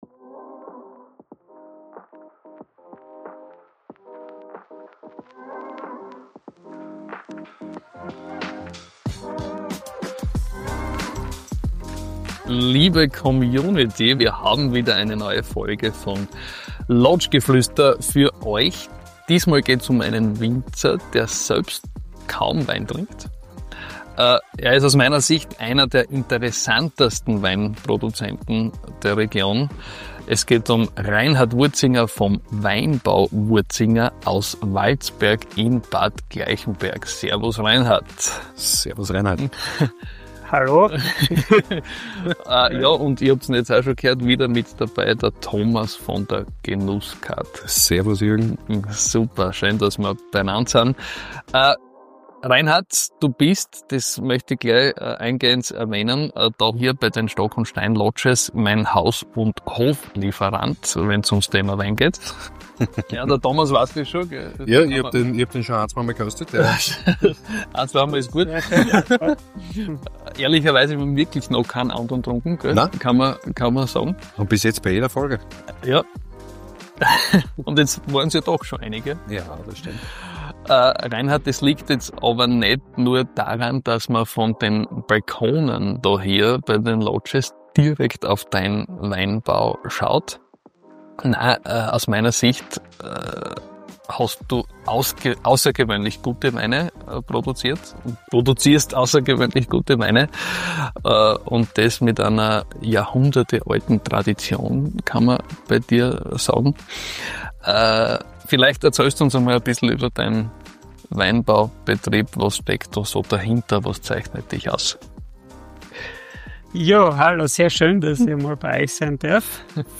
Ein Gespräch über Wein, Leidenschaft und die Freude am Genießen – mit einem Winzer, der nicht nur Reben, sondern auch Geschichten wachsen lässt.